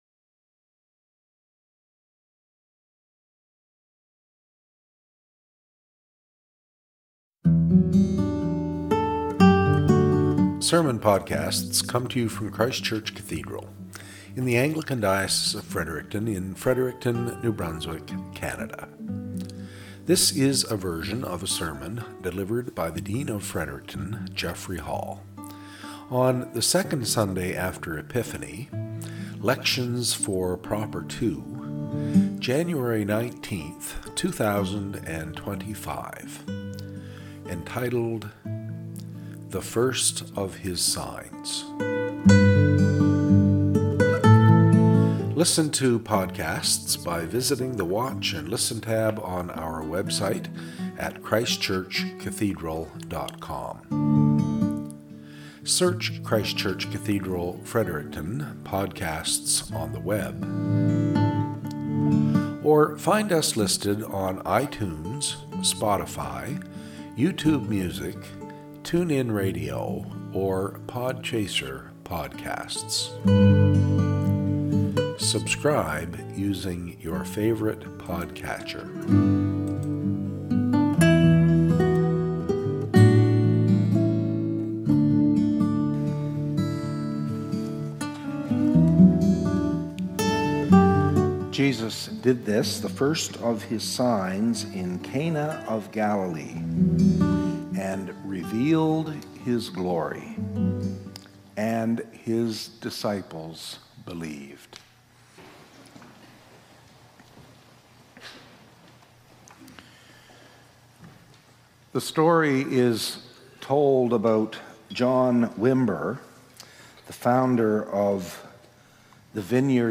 SERMON - "The First of His Signs"